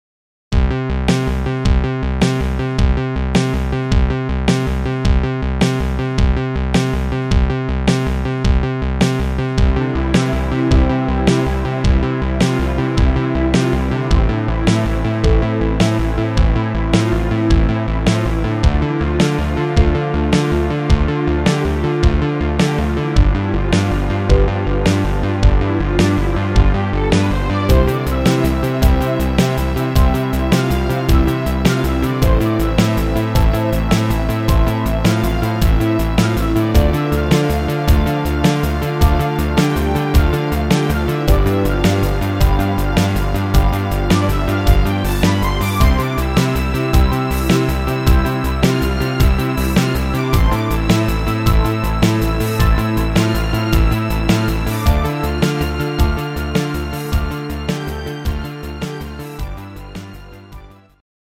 Rhythmus  Italo Disco
Art  Duette, Internationale Evergreens, Italienisch